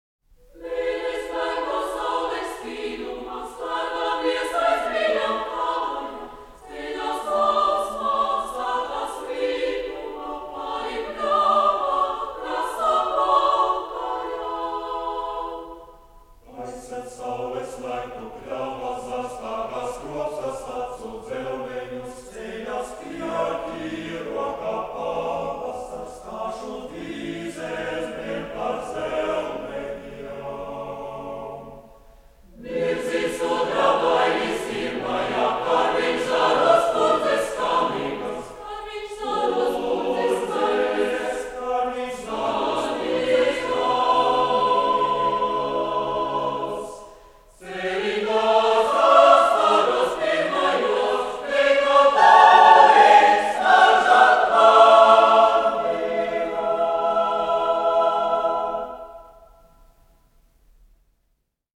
Kora mūzika
Mono kopija F-27198